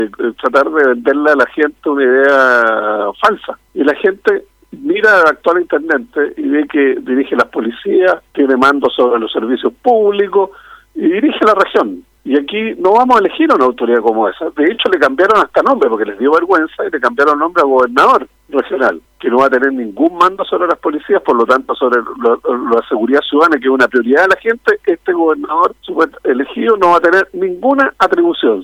En conversación con Radio Bío Bío, el legislador aseguró que este es un mal proyecto porque -a su juicio- se le a dicho a la gente que votará por un intendente, siendo que sus atribuciones serán notoriamente menores.